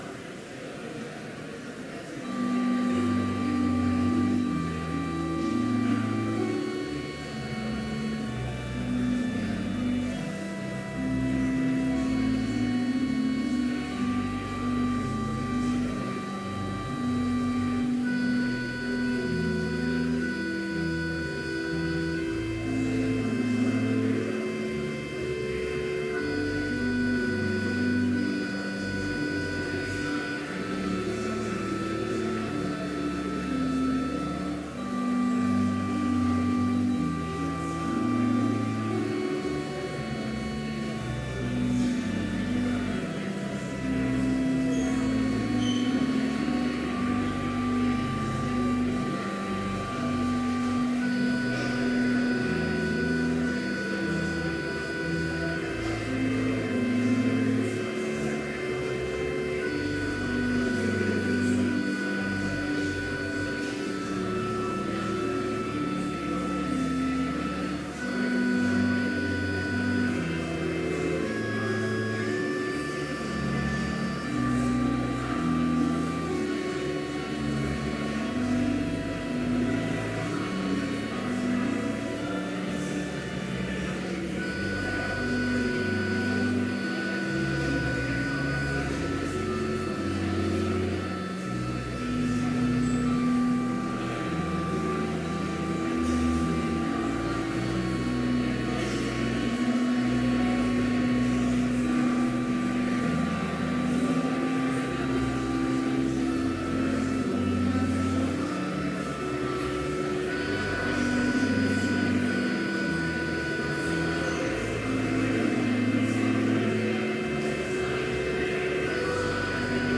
Complete service audio for Chapel - September 18, 2018